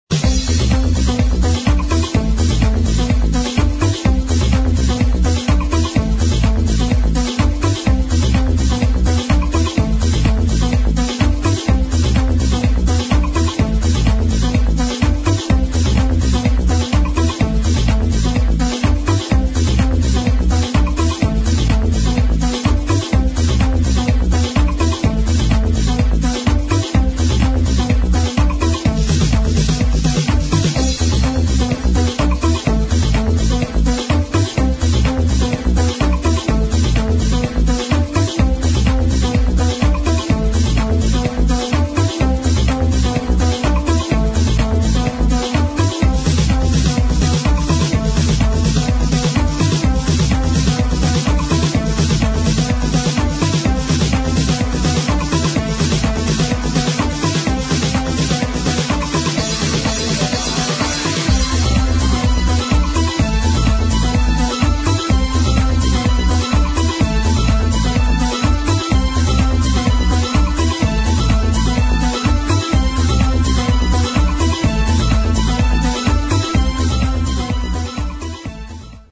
HOUSE / TECH HOUSE